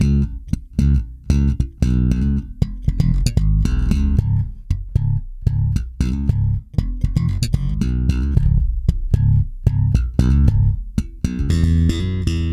-JP.THUMB.C#.wav